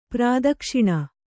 ♪ prādakṣiṇa